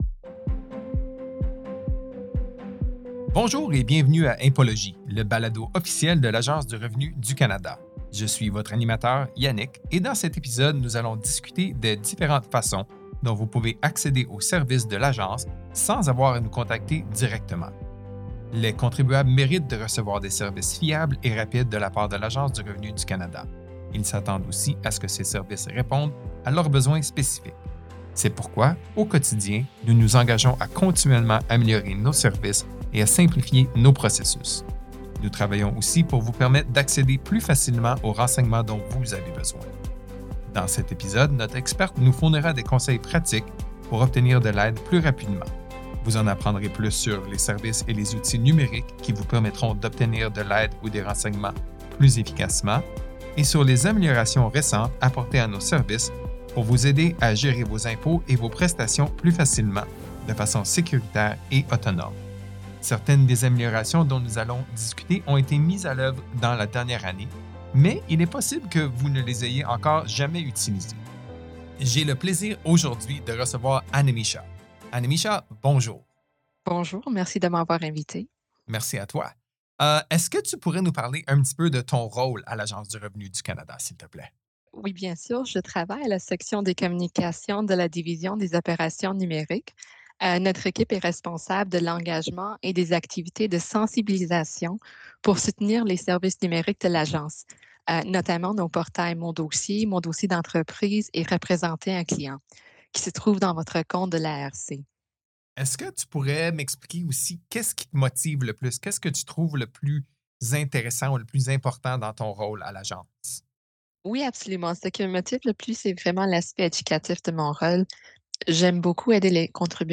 Dans cet épisode, notre invité expert vous aidera à gérer vos tâches courantes en matière d’impôt et de prestations en ligne. Nous discuterons des services et des outils numériques qui vous permettent de rapidement obtenir de l’aide ainsi que de nouvelles améliorations qui vous aideront à gérer toute votre situation fiscale.